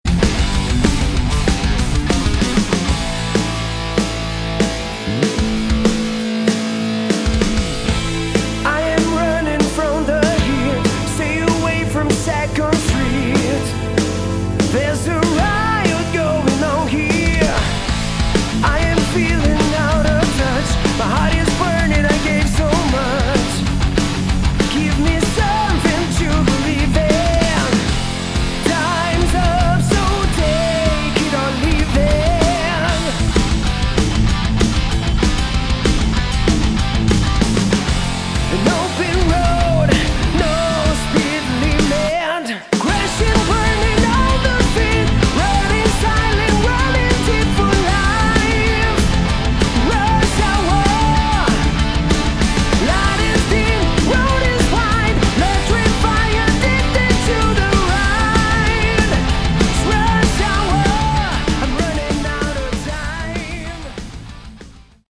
Category: Hard Rock
vocals
drums
guitars
bass